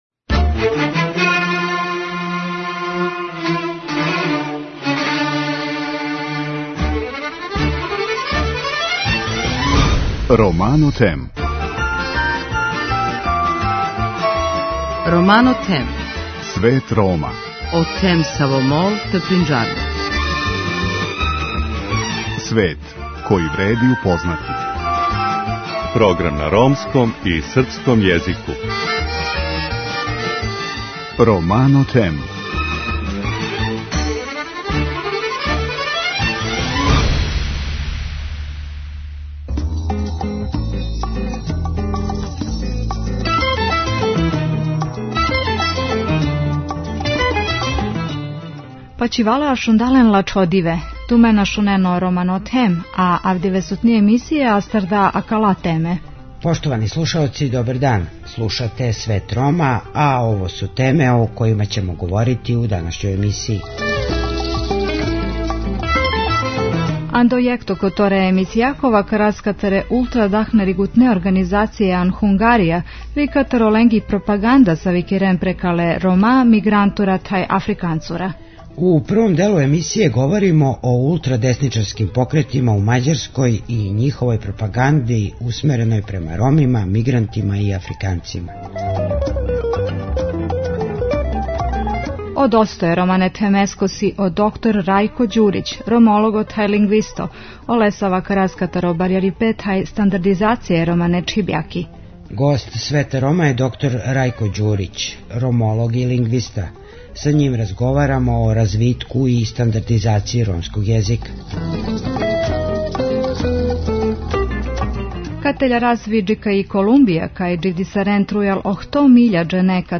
Гост Света Рома је доктор Рајко Ђурић ромолог и лингвиста. Са њим разговарамо о развитку и стандардизацији ромског језика.